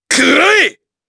Chase-Vox_Attack3_jp.wav